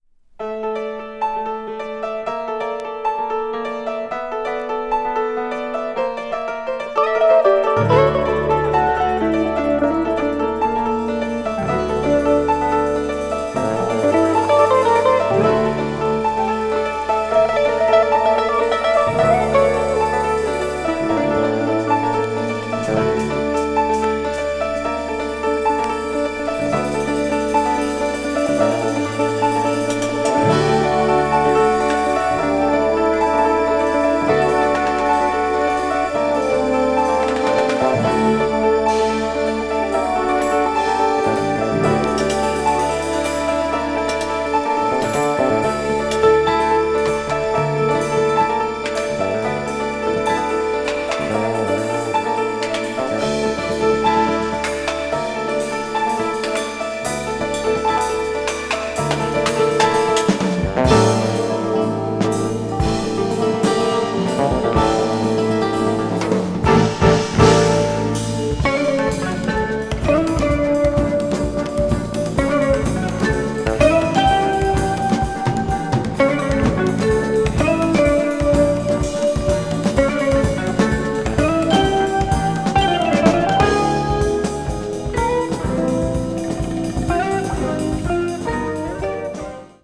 media : EX-/EX-(薄い擦り傷によるわずかなチリノイズが入る箇所あり)
contemporary jazz   crossover   fusion   new age jazz